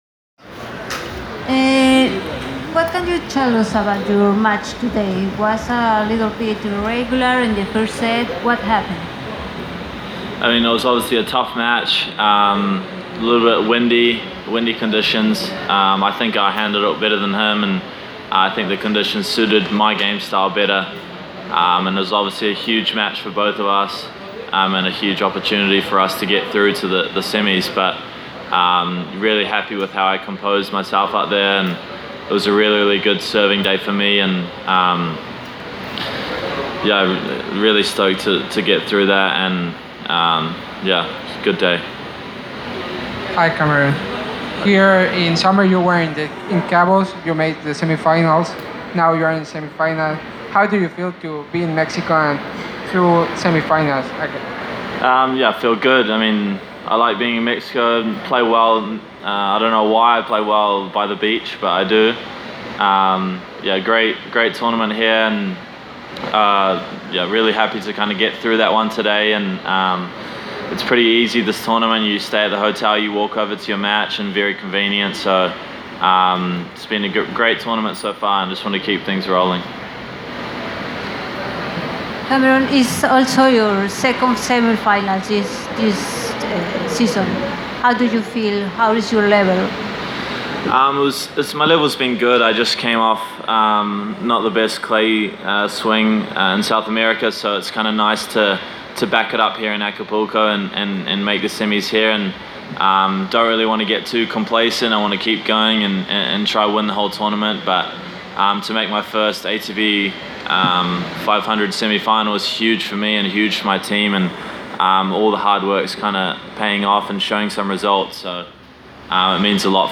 Press Conference – Cameron Norrie (28/02/2019)